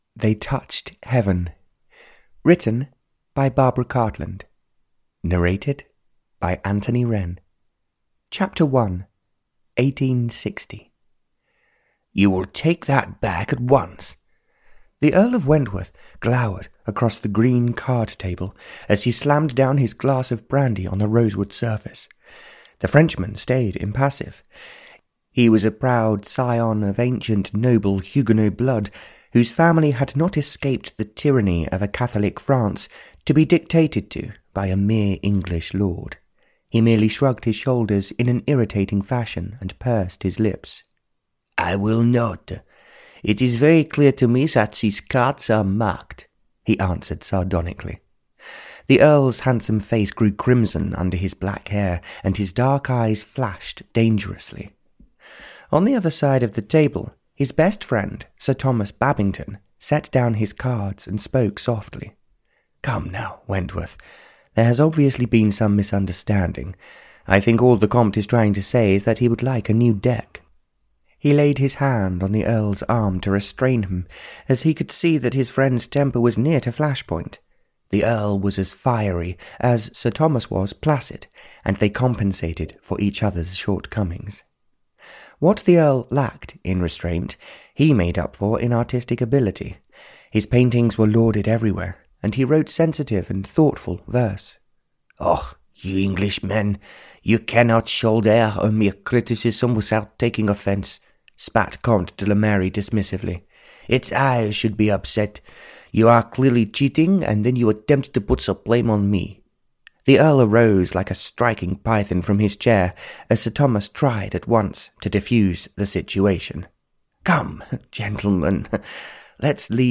They Touched Heaven (Barbara Cartland's Pink Collection 92) (EN) audiokniha
Ukázka z knihy